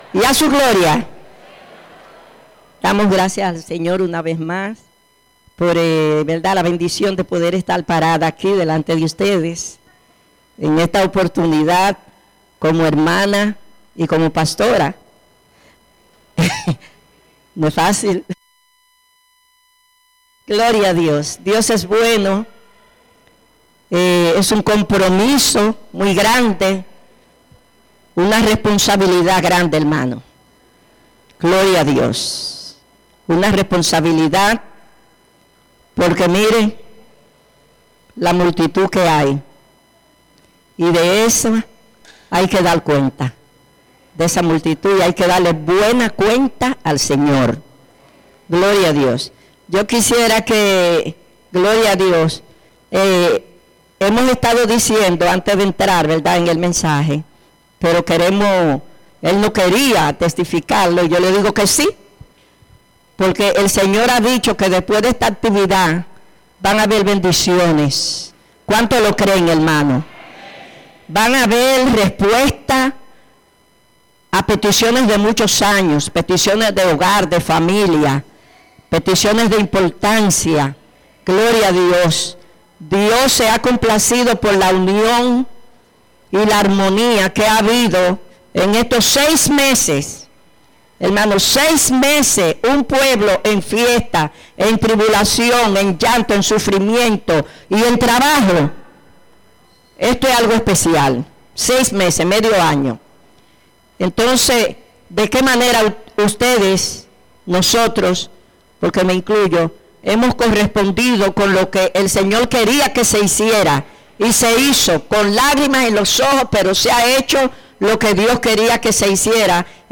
16 Mensaje